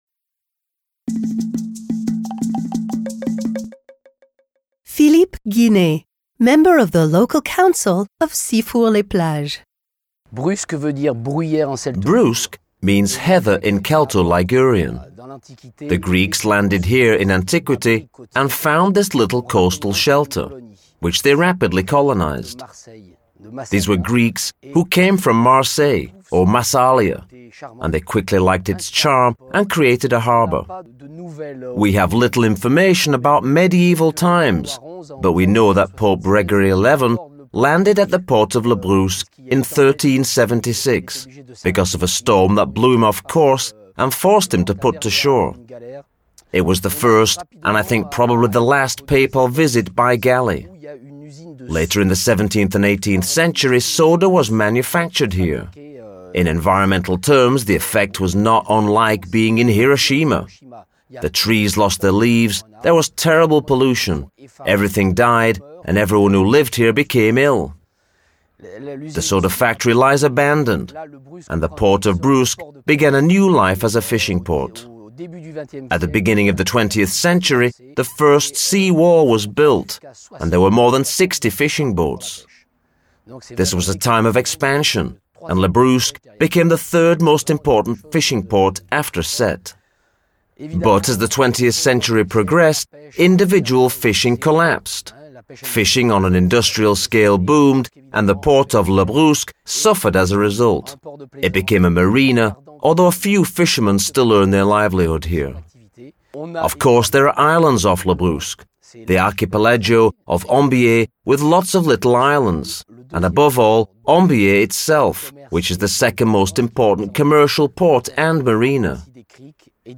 07_six-fours-les-plages_le_brusc_-_interview.mp3